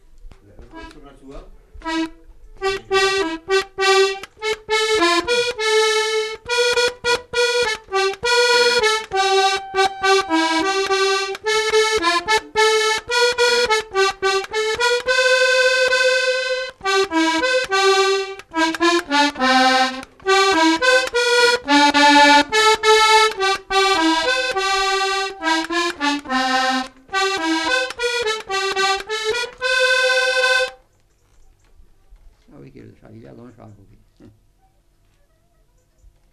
Valse
Genre : morceau instrumental
Instrument de musique : accordéon diatonique
Danse : valse